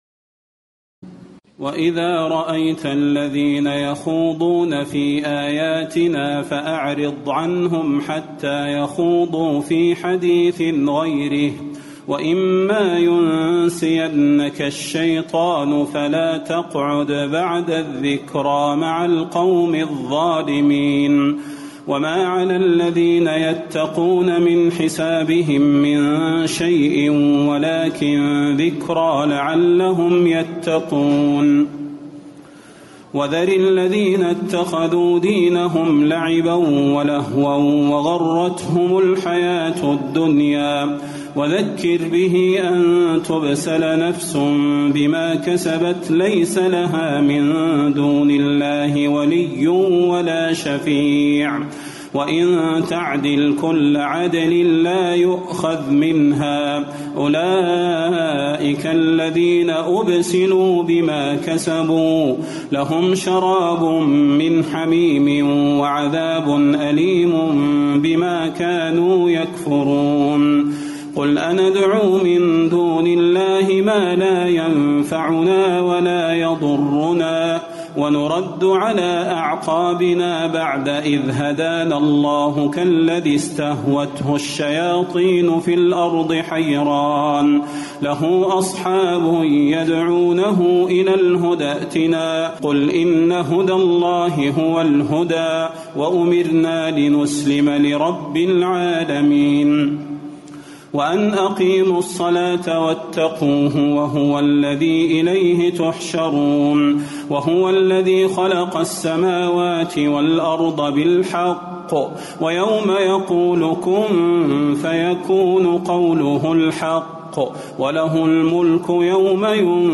تراويح الليلة السابعة رمضان 1437هـ من سورة الأنعام (68-140) Taraweeh 7 st night Ramadan 1437H from Surah Al-An’aam > تراويح الحرم النبوي عام 1437 🕌 > التراويح - تلاوات الحرمين